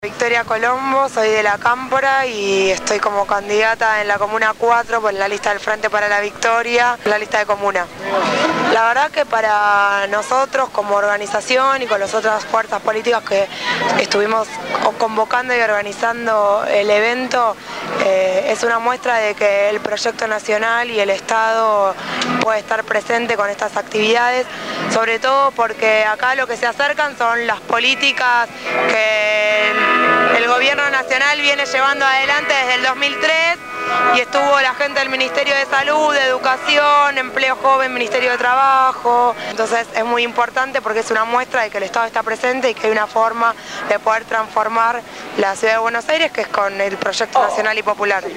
El sábado 4 de junio se llevó adelante la jornada solidaria «Somos Ambiente» en la Villa 21-24.